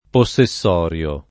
vai all'elenco alfabetico delle voci ingrandisci il carattere 100% rimpicciolisci il carattere stampa invia tramite posta elettronica codividi su Facebook possessorio [ po SS e SS0 r L o ] agg. (giur.); pl. m. ‑ri (alla lat. -rii )